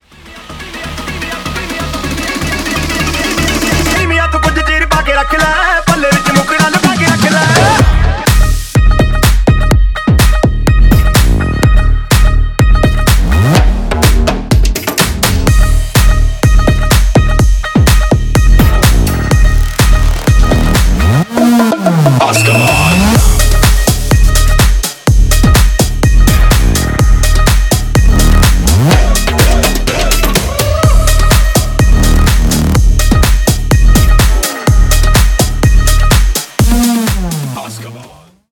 • Качество: 320 kbps, Stereo
Ремикс
Танцевальные
Индийские